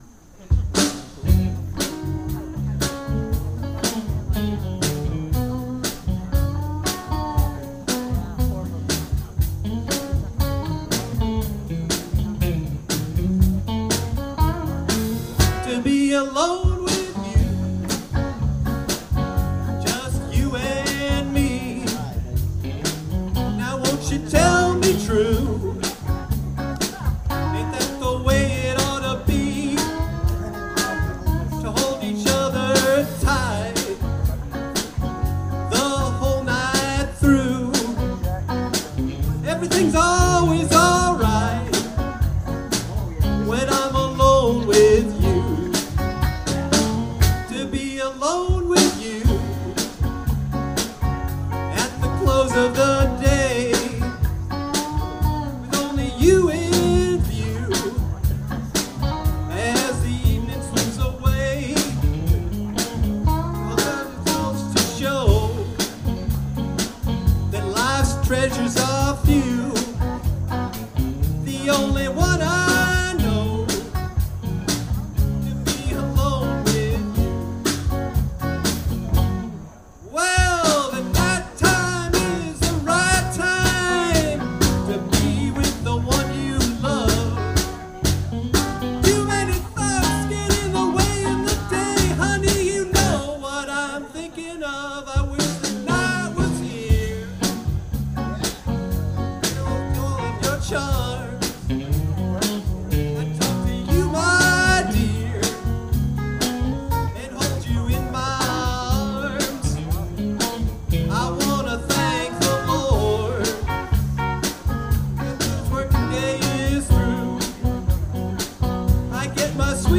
guitarist
bassist
drummer
To Be Alone with You (audio only) Bob Dylan Arlington Porchfest 2022